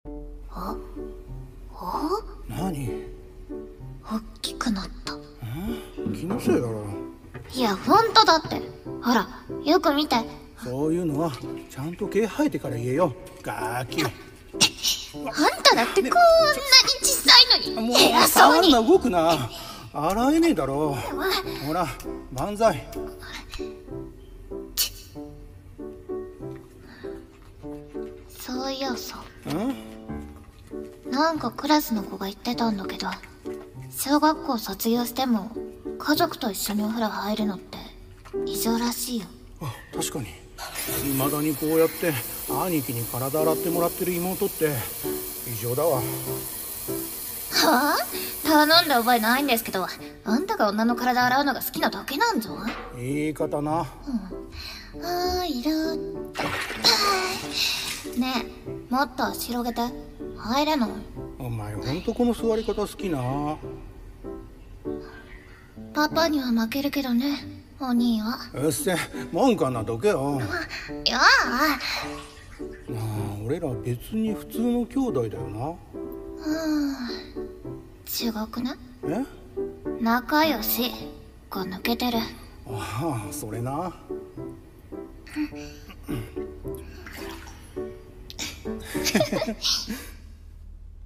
【声劇台本】とある普通の仲の良い兄妹の話【二人声劇】